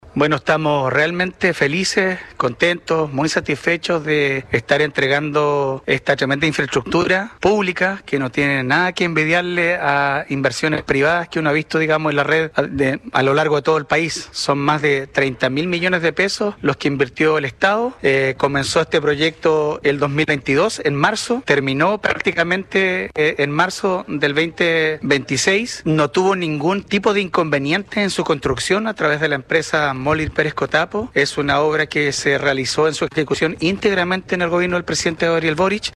Por su parte, el alcalde de Queilen, Marcos Vargas, valoró el impacto social de la obra.
alcalde-queilen-2-hospital-.mp3